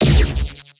electro_explode.wav